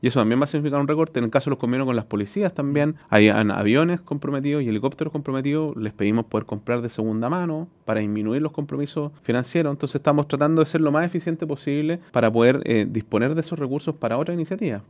En la instancia, el gobernador también abordó la reasignación de presupuesto para algunos proyectos.